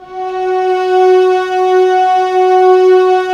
Index of /90_sSampleCDs/Roland LCDP13 String Sections/STR_Violas FX/STR_Vas Sordino